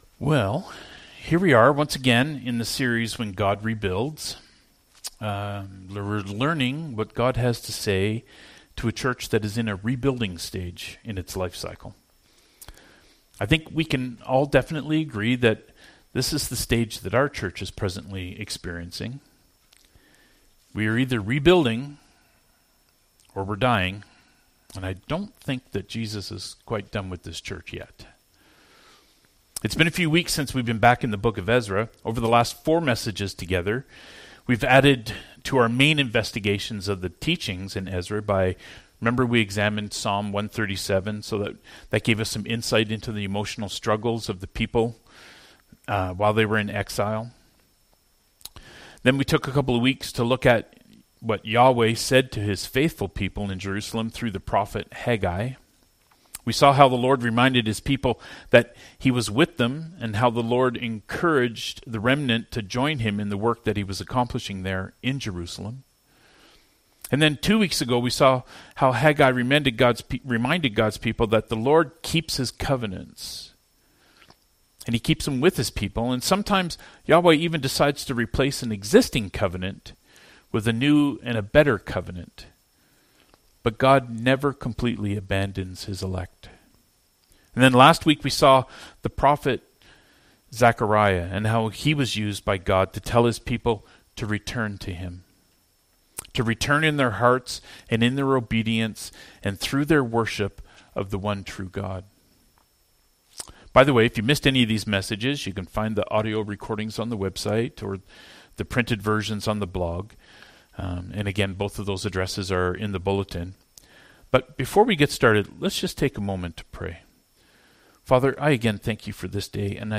Passage: Ezra 6: 1-22 Service Type: Sermons « “Return to Me”, say the Lord!